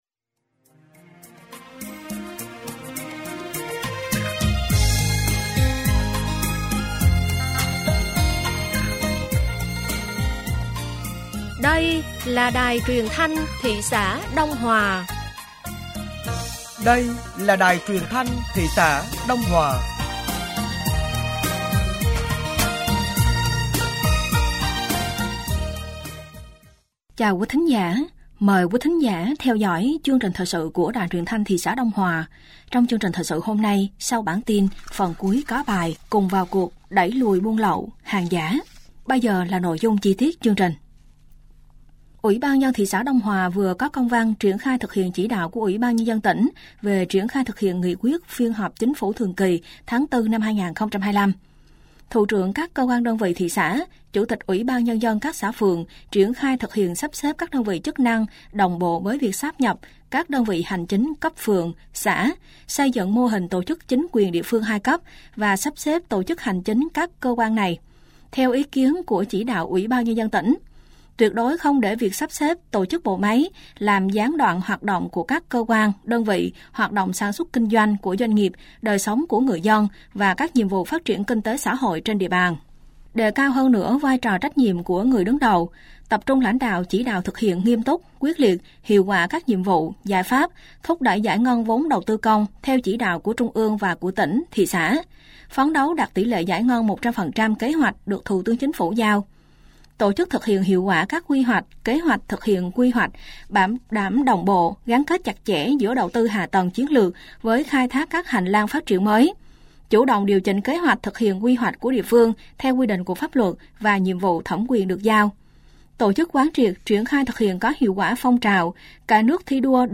Thời sự tối ngày 29/5 sáng ngày 30/5/2025